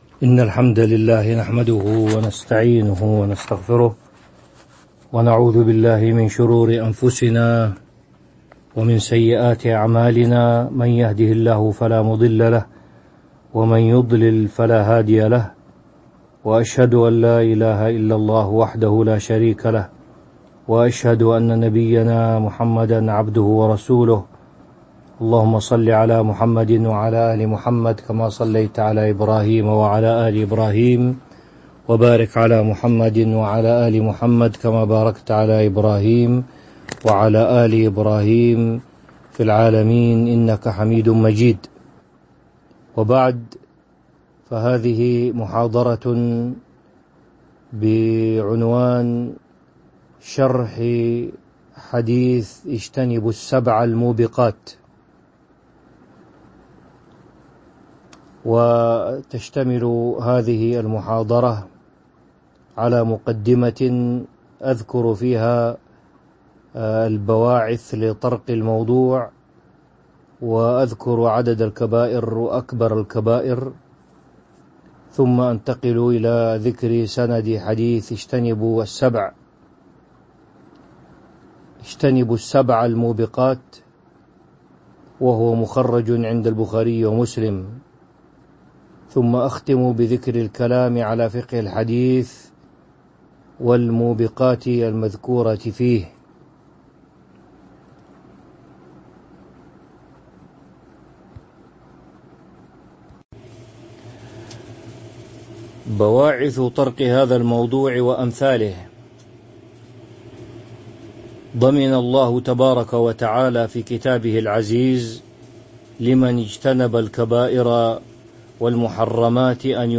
تاريخ النشر ١٩ ذو الحجة ١٤٤٢ هـ المكان: المسجد النبوي الشيخ